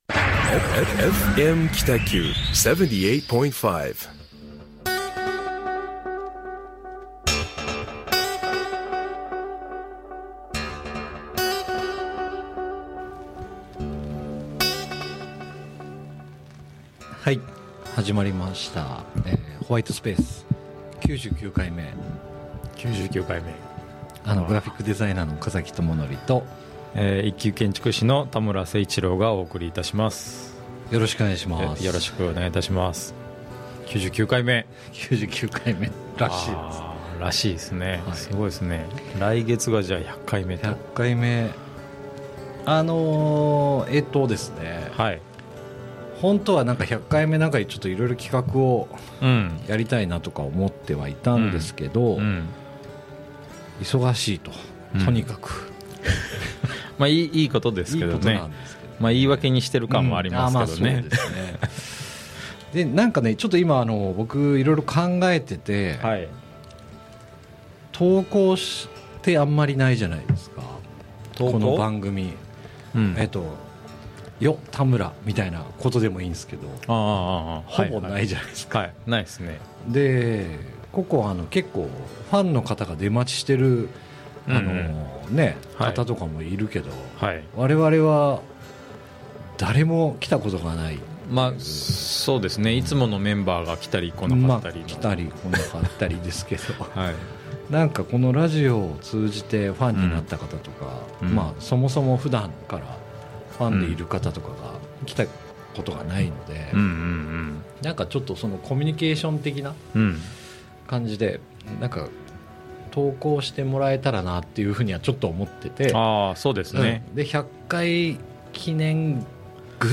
北九州市のコミュニティFM放送局 FMKITAQ ラジオ番組「ホワイトスペース」毎月第4月曜日 20:00〜放送中♪